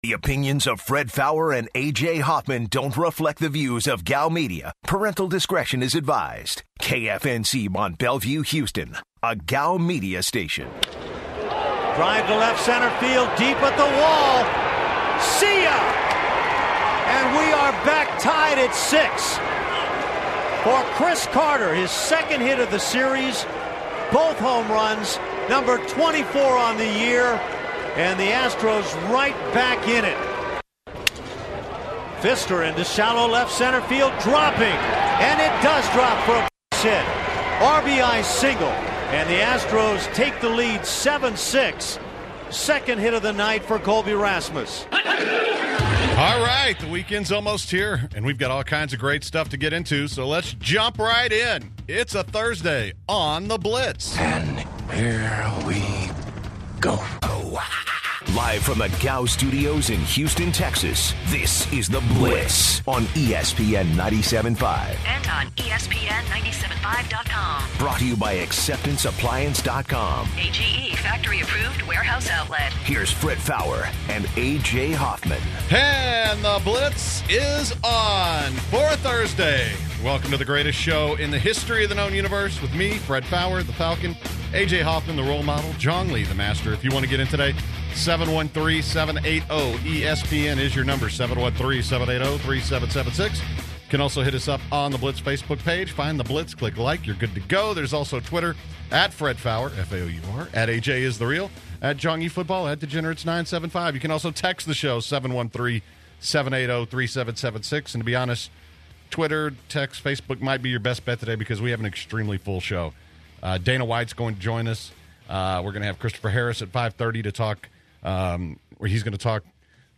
It's Thursday! to open the show, the guys react to the Astros win last night. Then, they interview Dana White- UFC President.